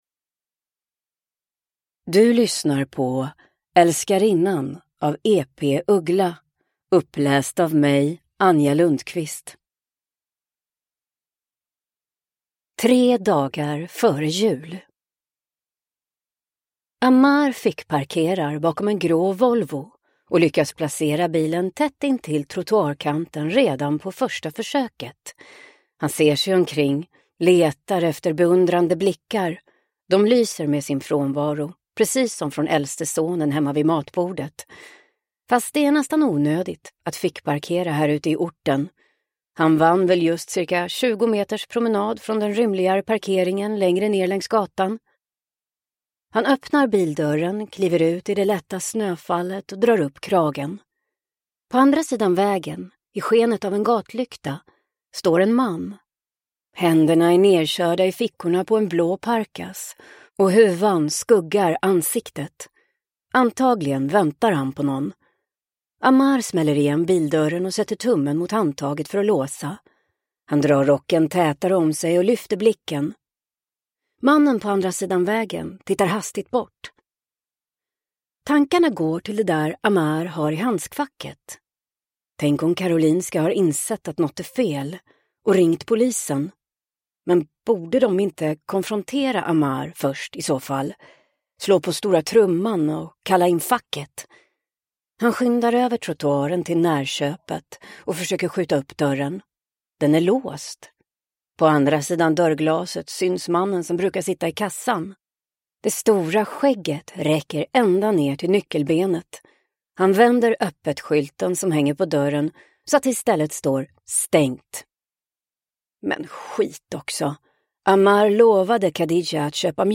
Älskarinnan – Ljudbok